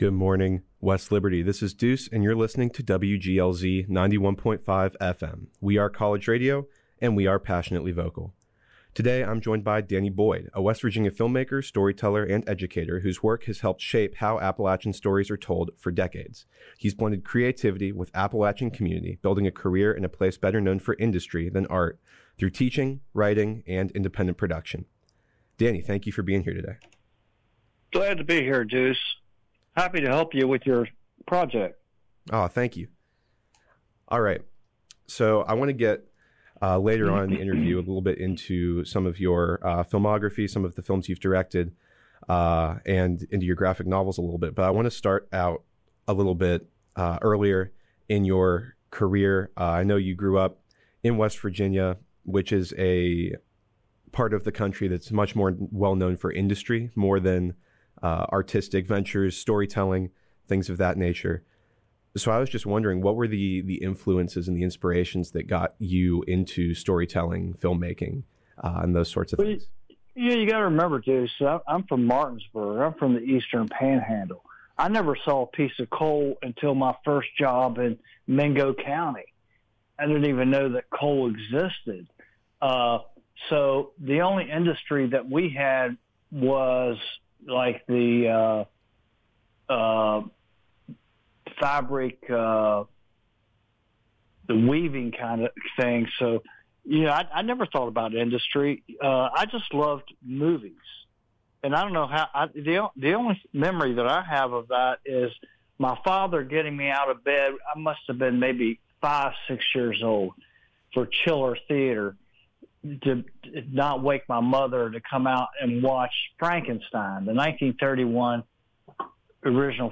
Whether you’re a filmmaker, writer, or simply someone who cares about Appalachian storytelling, this interview offers insight into how passion, resilience, and place can shape a lifelong creative path.